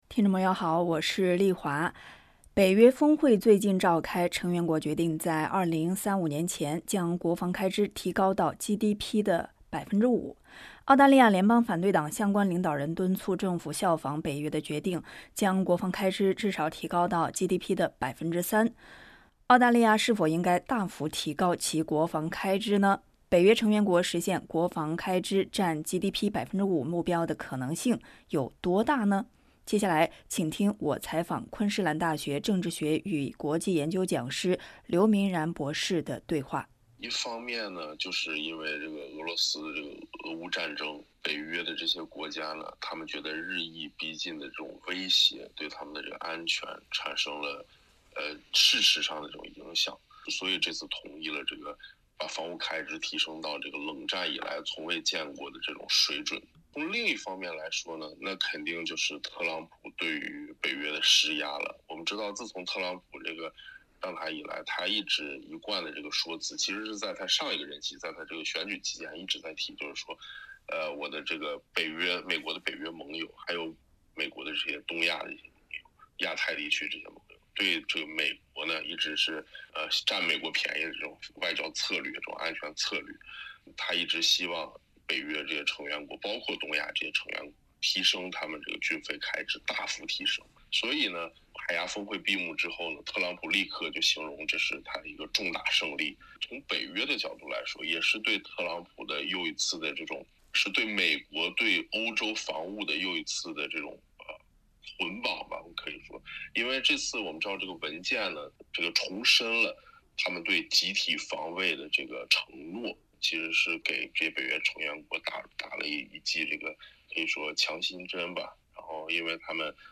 嘉宾观点，不代表本台立场。